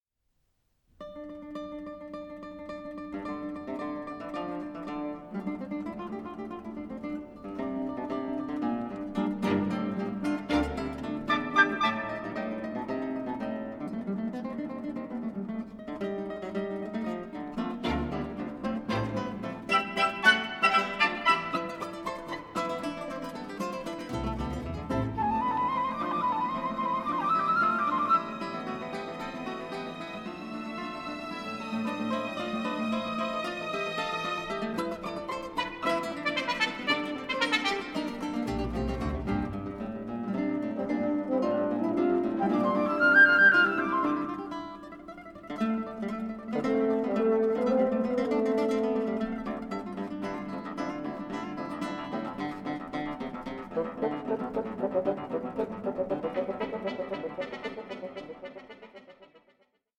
for 2 Guitars and Orchestra
Presto